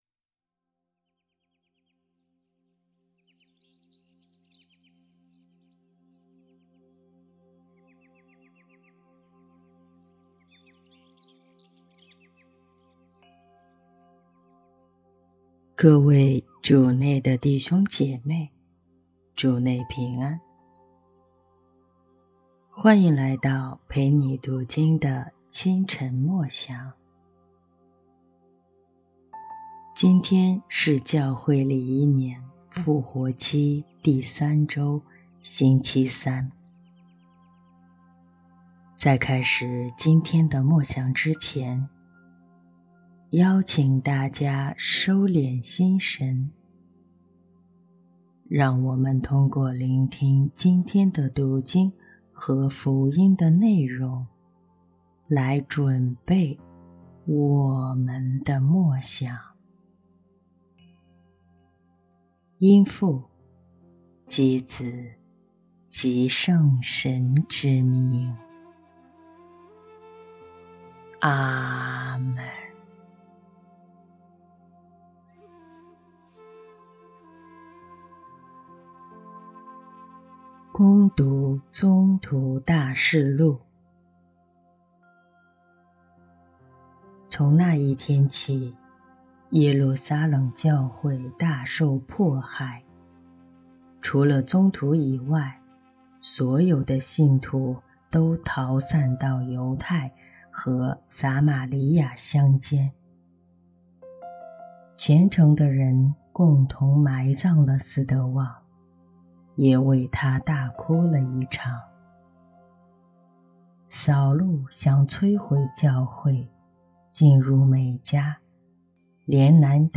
朗读者语调太低沉了，让人听了提不起精神，请用阳光明媚的声音。